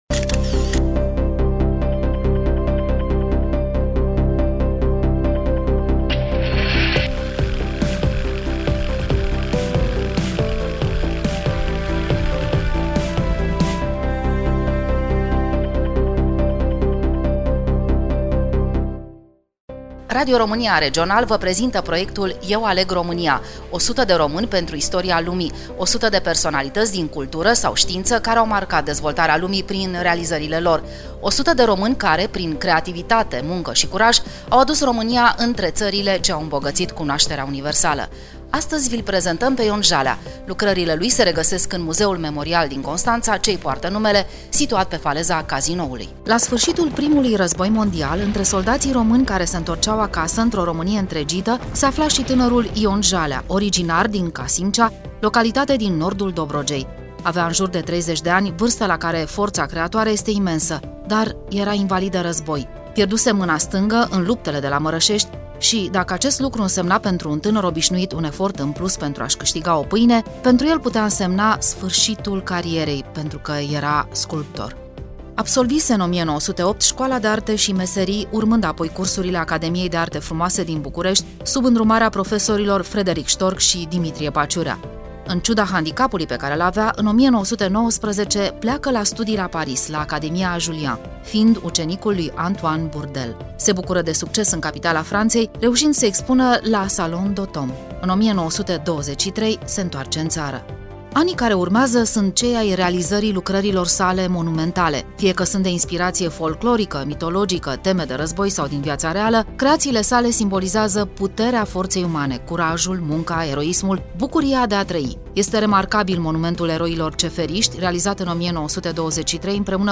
Studioul: Radio Romania Constanţa